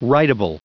Prononciation du mot rideable en anglais (fichier audio)
Prononciation du mot : rideable